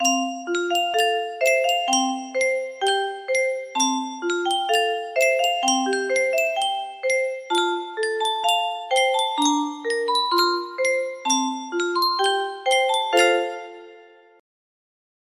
Yunsheng Music Box - Unknown Tune 1078 music box melody
Full range 60